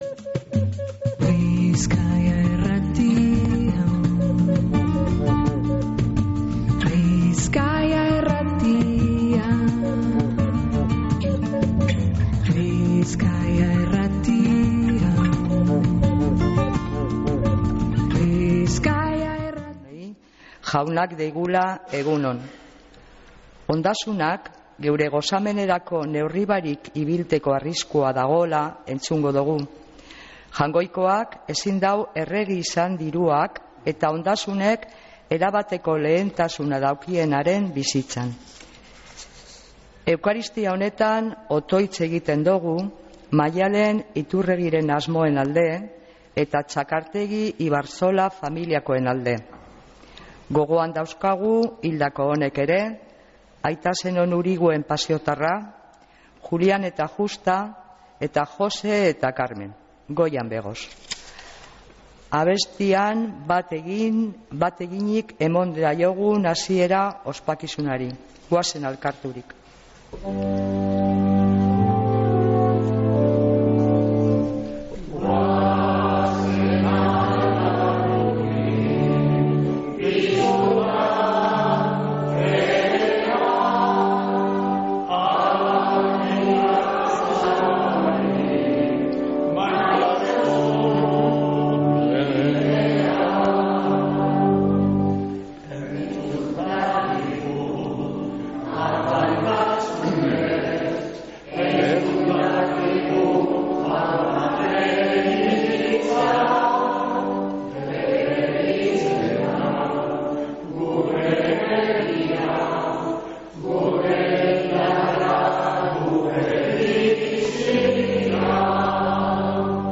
Mezea San Felicisimotik